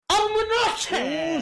aminake orijinal Meme Sound Effect
Category: Reactions Soundboard